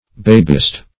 Babist \Bab"ist\, n.